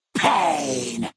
11 KB Category:Fallout: New Vegas creature sounds 1
FNV_GenericFeralGhoulAttack_Pain.ogg